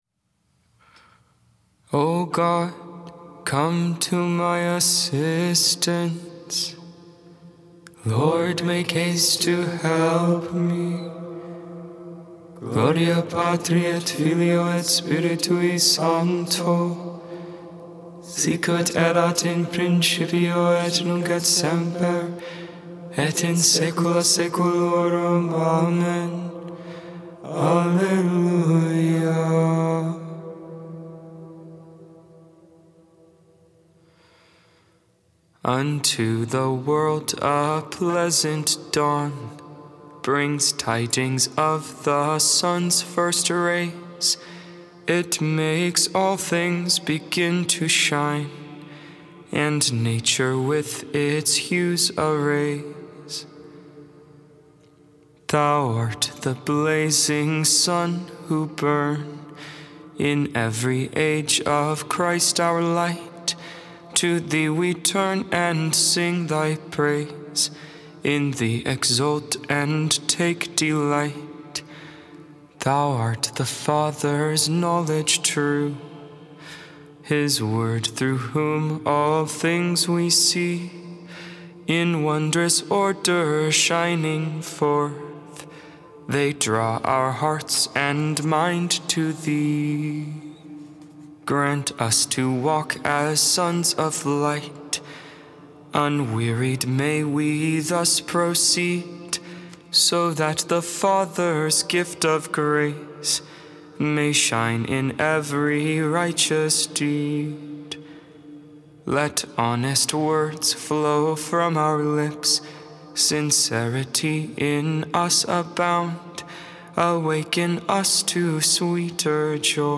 9.20.22-lauds-tuesday-b.flat.mp3